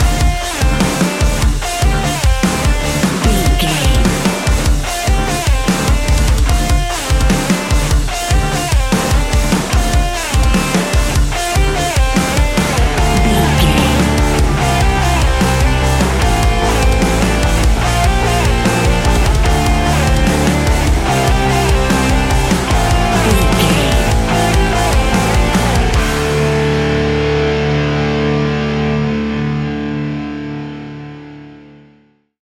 Ionian/Major
D♭
instrumentals